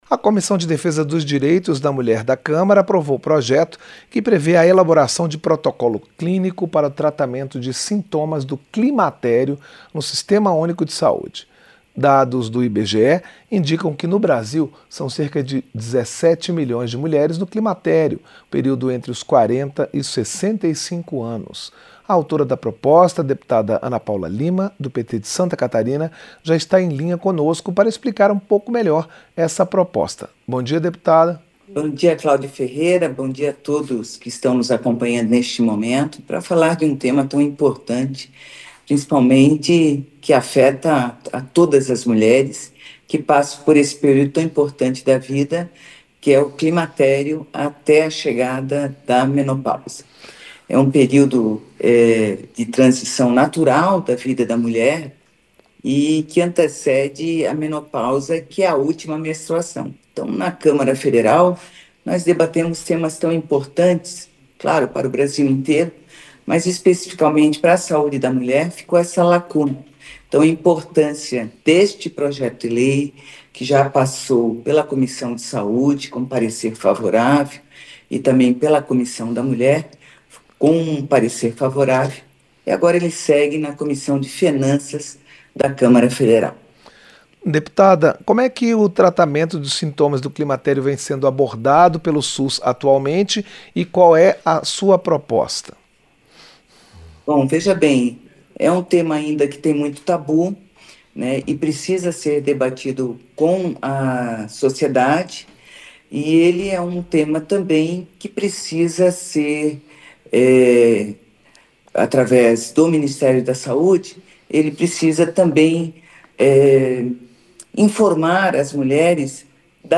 Entrevista - Dep. Ana Paula Lima (PT-SC)